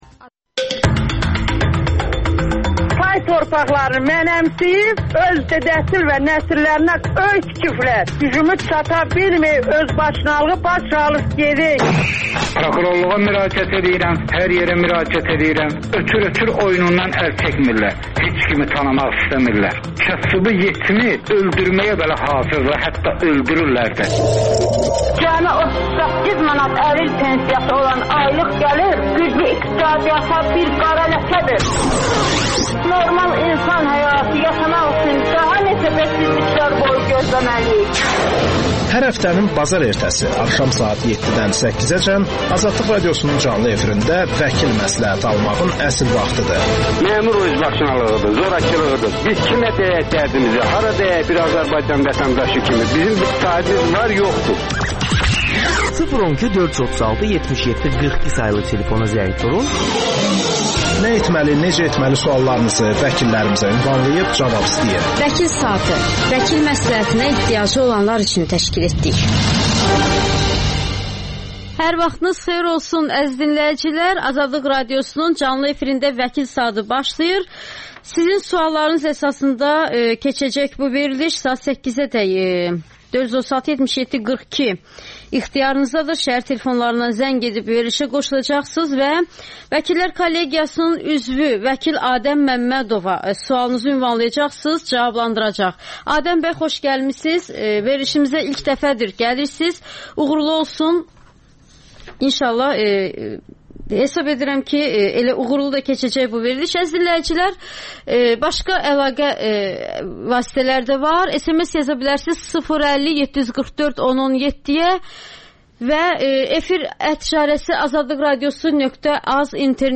Xəbərlər